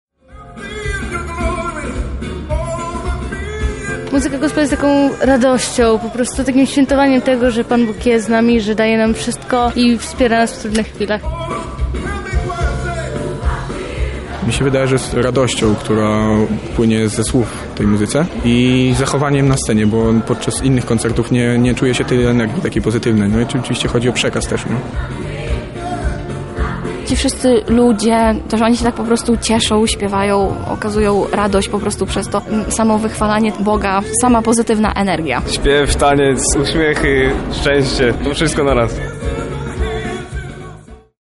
Radosne dźwięki rozbrzmiały w Chatce Żaka – wszystko za sprawą Wiosny Gospel.